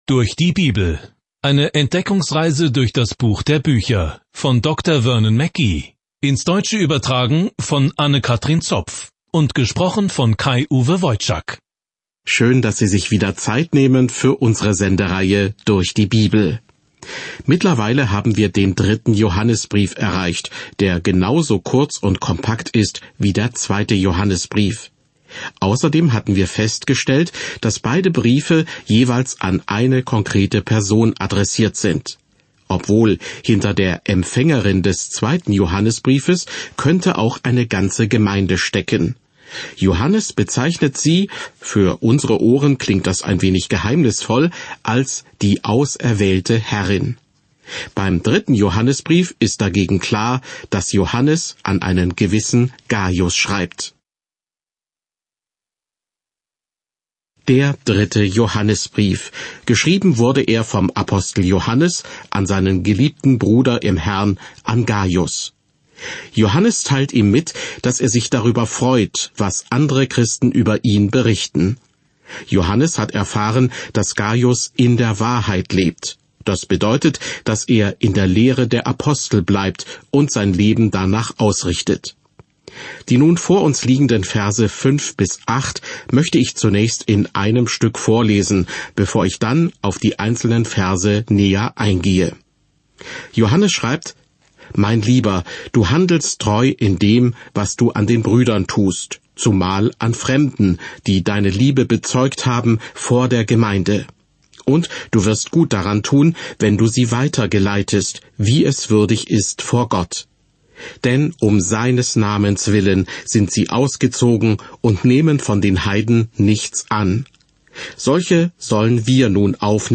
Johannes, während Sie sich die Audiostudie anhören und ausgewählte Verse aus Gottes Wort lesen.